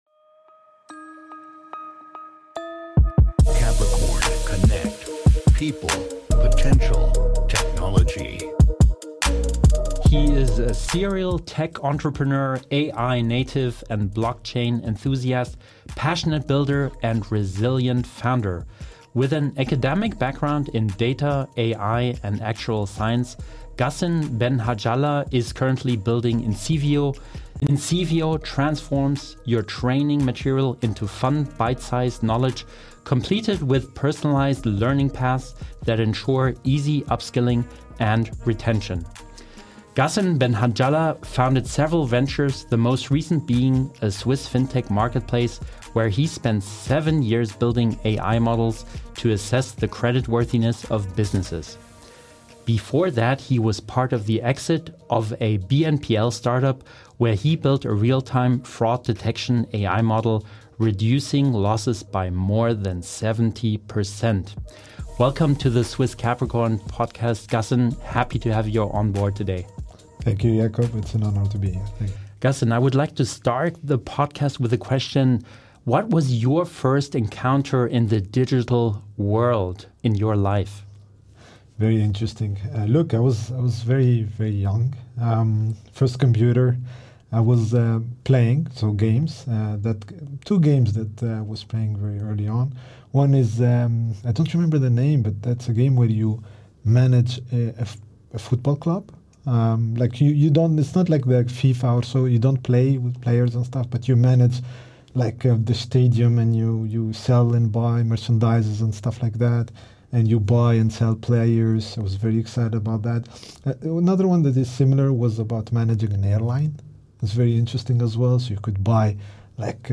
A raw, honest, and energizing conversation for founders, operators, and anyone navigating startups in the AI era.